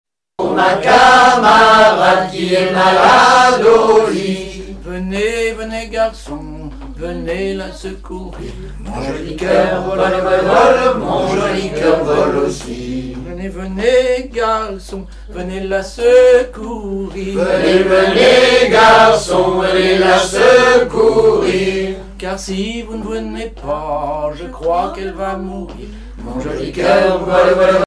Localisation Hattenville
danse : ronde à trois pas
Chansons et commentaires
Pièce musicale inédite